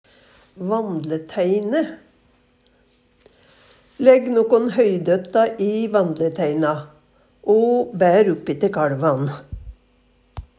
vandleteine teine, her berareiskap av fletta vidje til å bera høy i Eintal ubunde Eintal bunde Fleirtal ubunde Fleirtal bunde vandleteine -teina -teinu -teinun Eksempel på bruk Legg nokon høydøtta i vandleteina, o bær uppi te kaLvan. Høyr på uttala Ordklasse: Substantiv hokjønn Kategori: Planteriket Reiskap og arbeidsutstyr Skogbruk Handverk (metall, tre, lær) Attende til søk